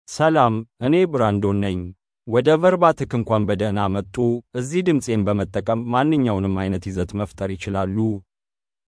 BrandonMale Amharic AI voice
Brandon is a male AI voice for Amharic (Ethiopia).
Voice sample
Male
Brandon delivers clear pronunciation with authentic Ethiopia Amharic intonation, making your content sound professionally produced.